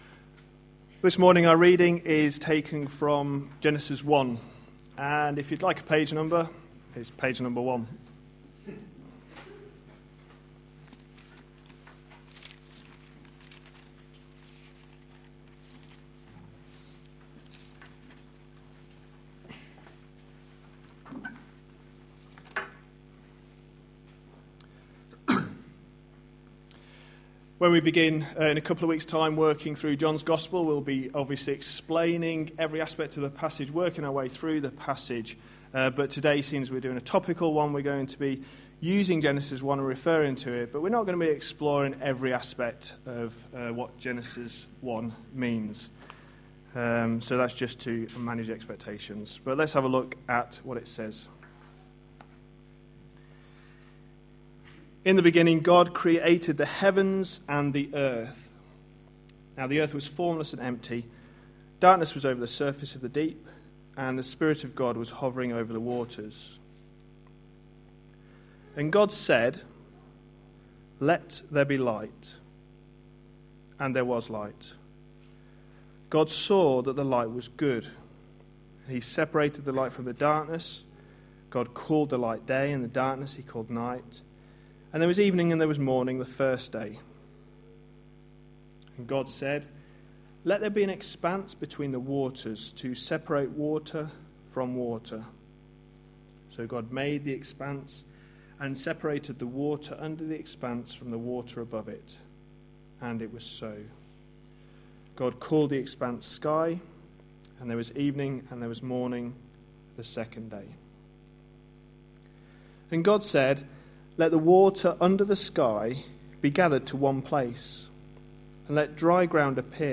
A sermon preached on 19th January, 2014, as part of our The gospel is the reason series.